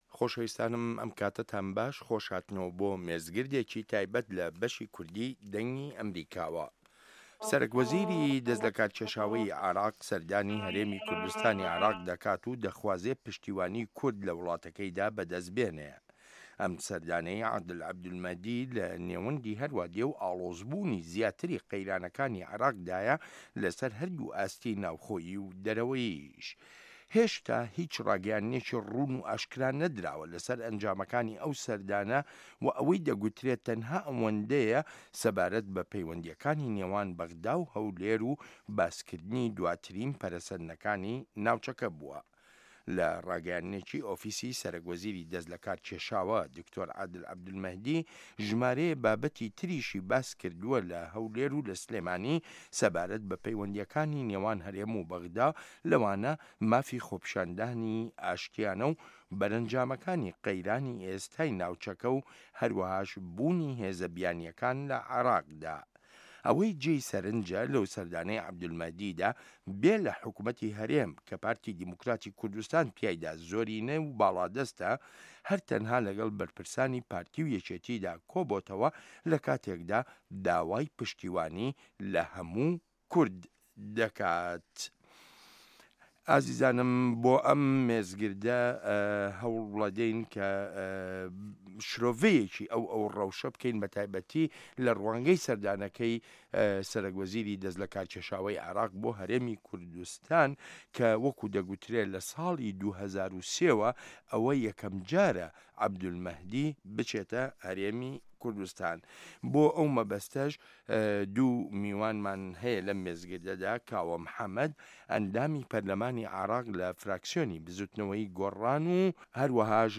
مێزگرد: بەغدا و هەولێر و ئایندەی ڕەوشی عێراق